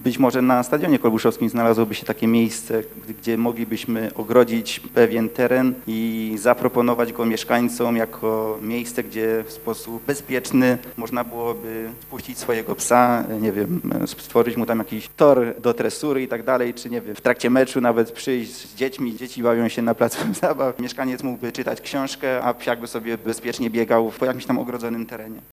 To rejon stadionu przy ulicy Wolskiej. Mówi pomysłodawca parku dla psów radny Piotr Panek.